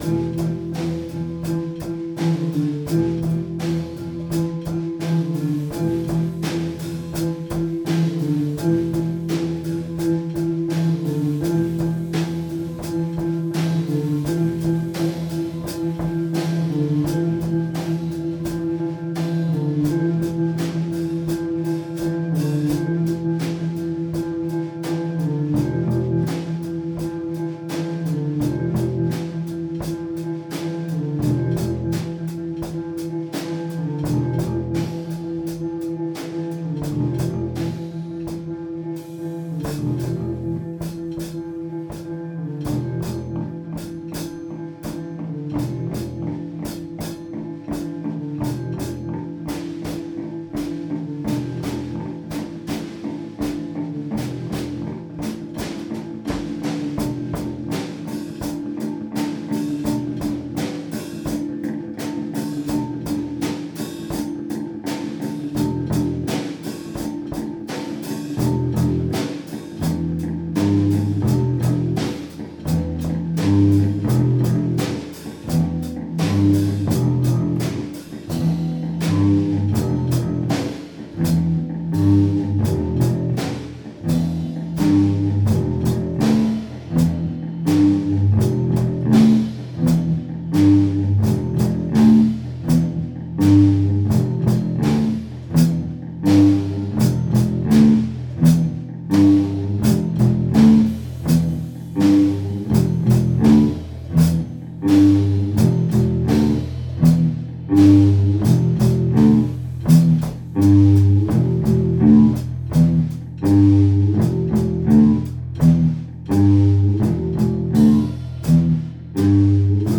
drums and bass
some layers
3/4 bass